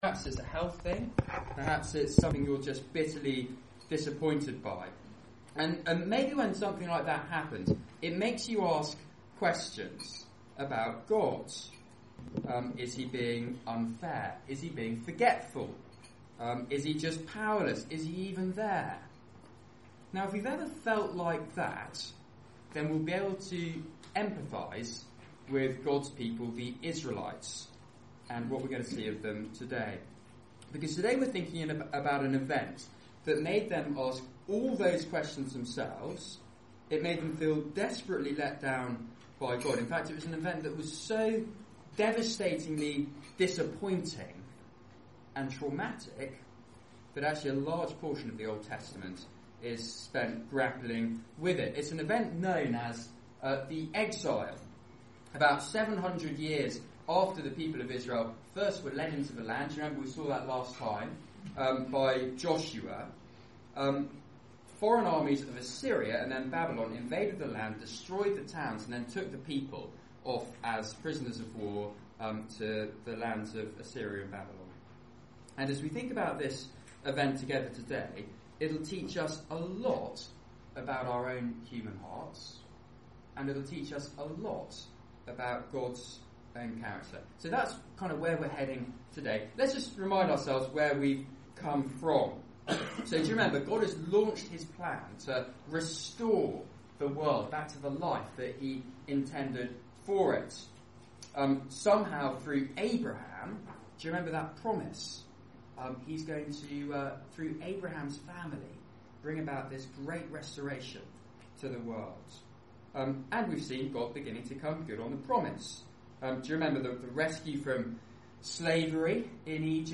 Media for Seminar